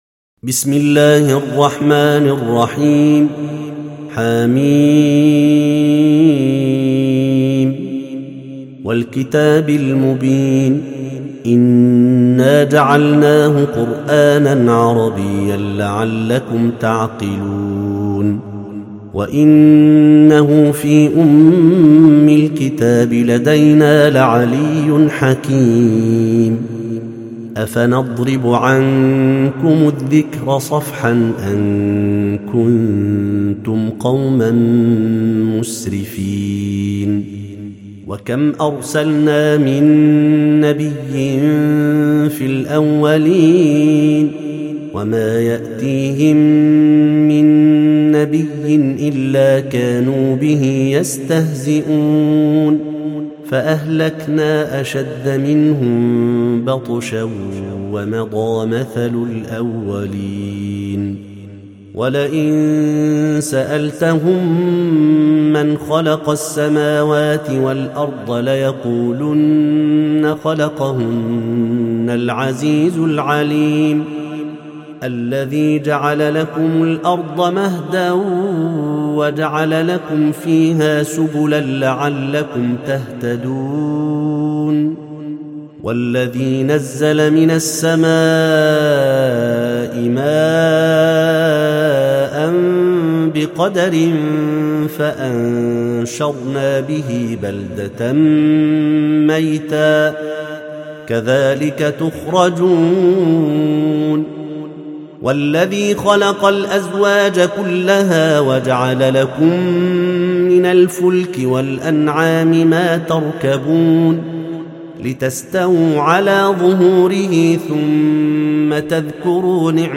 سورة الزخرف - المصحف المرتل (برواية حفص عن عاصم)